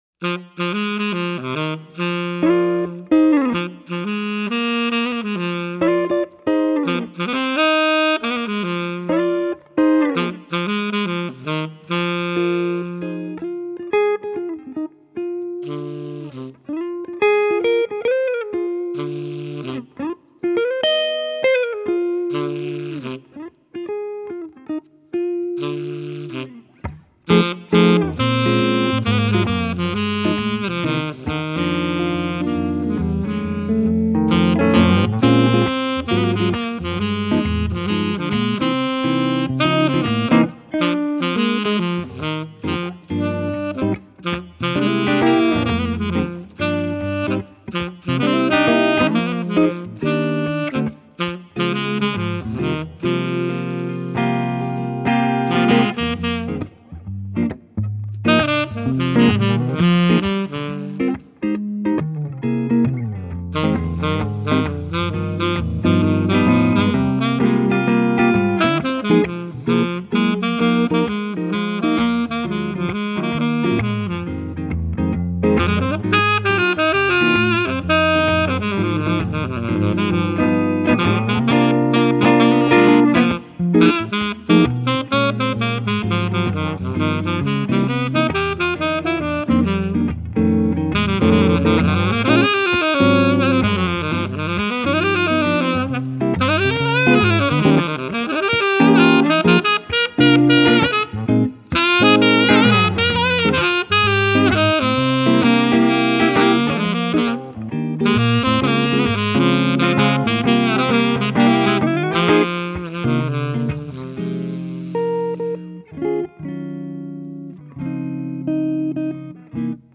(gitara i saksofon)